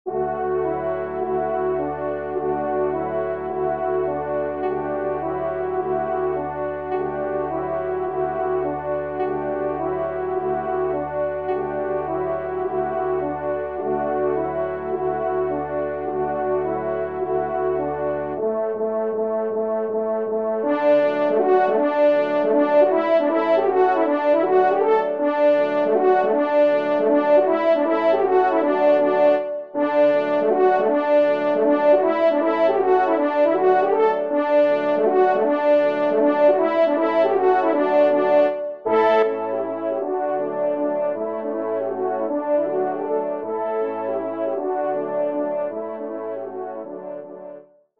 Danses polonaises
ENSEMBLE